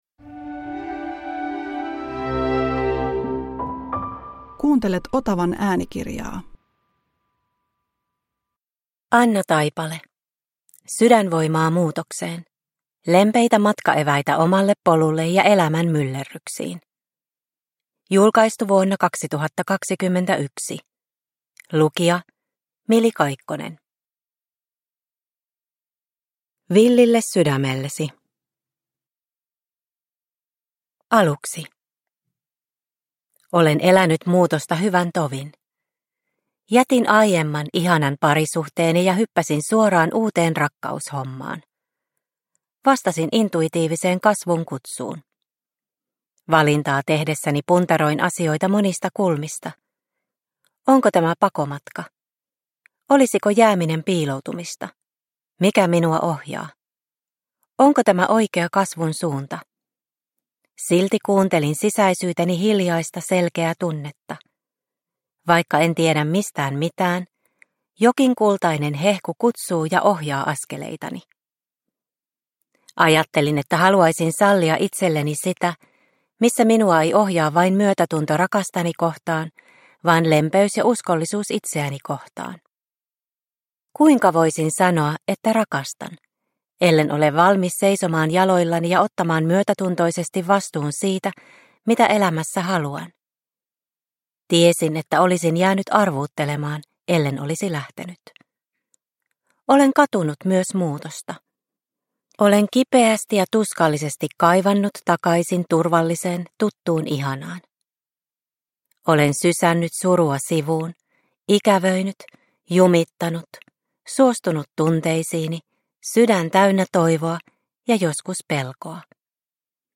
Sydänvoimaa muutokseen – Ljudbok – Laddas ner